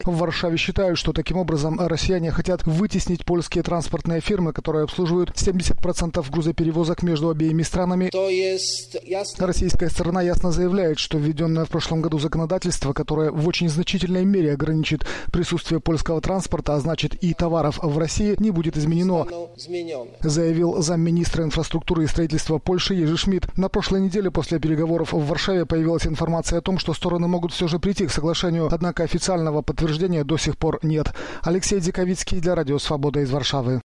Рассказывает корреспондент Радио Свобода